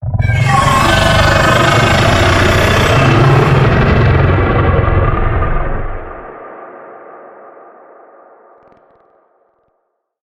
CrpArachnoidCamperVocalDemoStinger.ogg